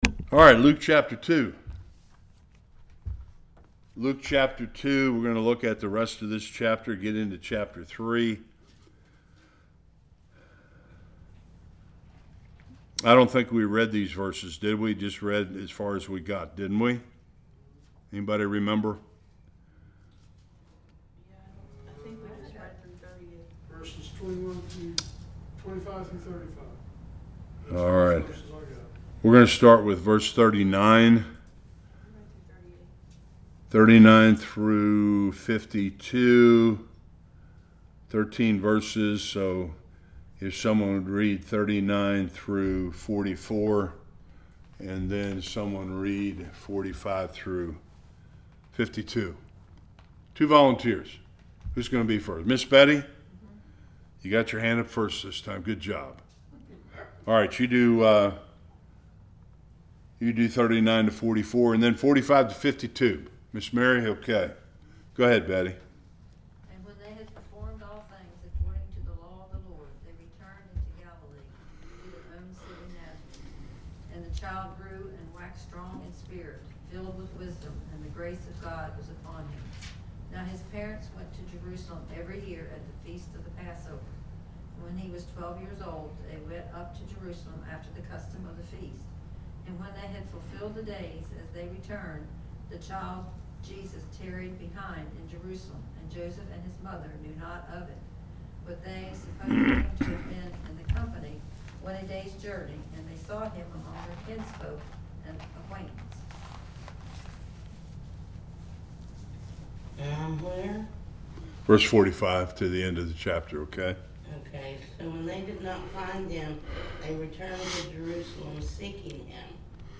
1-20 Service Type: Bible Study Twelve year old Jesus asking and answering questions in the temple.